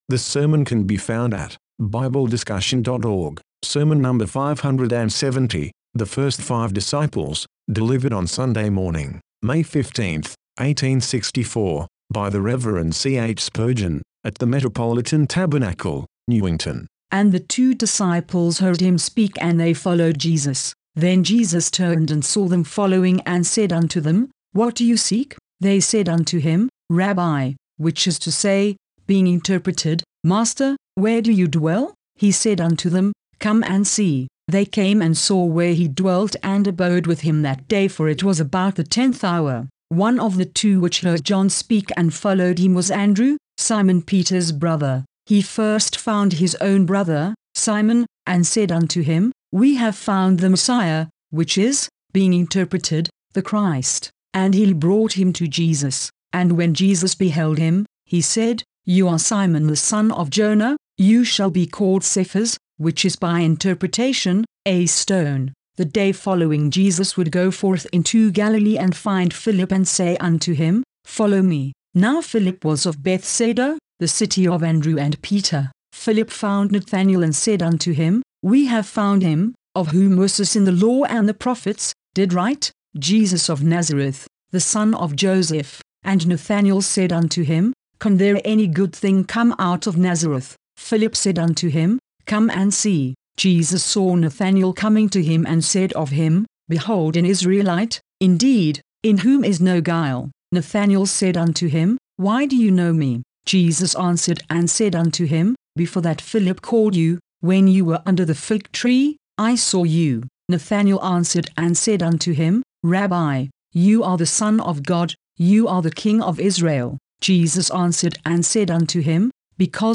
Sermon #570 The First Five Disciples Delivered On Sunday Morning, May 15, 1864, By The Rev. C. H. Spurgeon, At The Metropolitan Tabernacle, Newington.